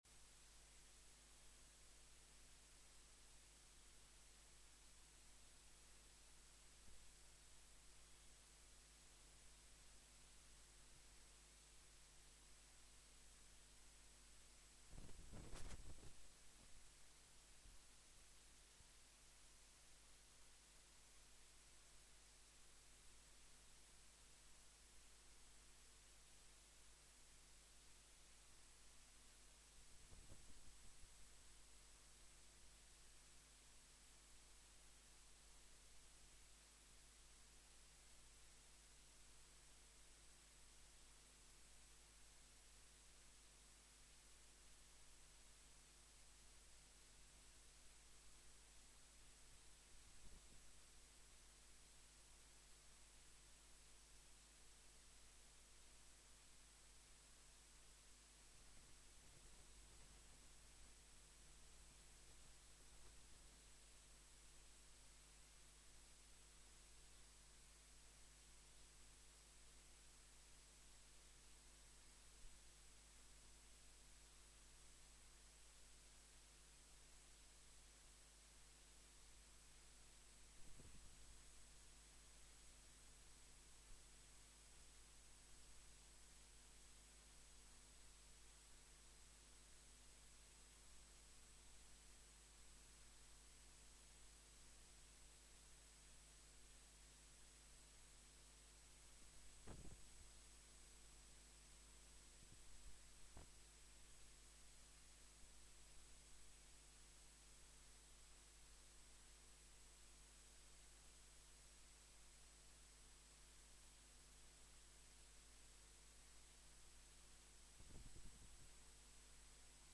Sermons | Marion Vineyard Christian Fellowship